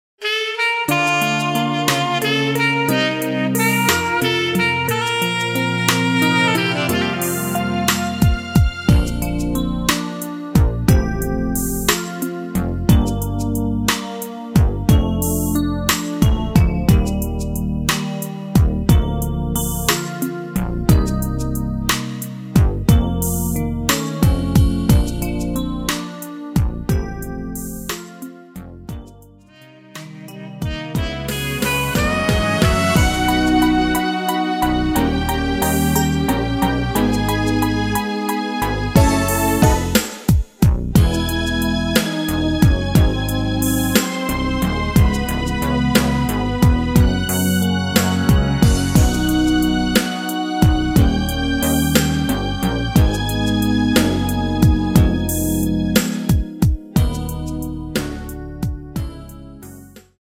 전주가 길어서 앞에 코러스 부분은 노래 부르기 편하게 제작하지 않았습니다.
원곡의 보컬 목소리를 MR에 약하게 넣어서 제작한 MR이며